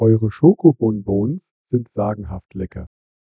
A free to use, offline working, high quality german TTS voice should be available for every project without any license struggling.
Added Griffin Lim vocoder samples
sample01-griffin-lim.wav